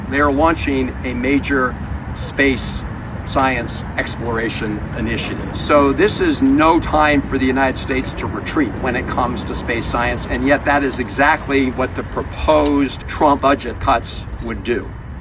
Van Hollen said the US cannot fall second to China…